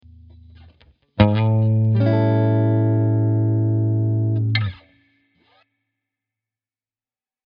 Guitar Chord Slide – Guitar Chord Tips
First slide the chord one fret up using all the notes in the chord.
Once you stroke the strings with your pick, immediately slide the chord one fret up.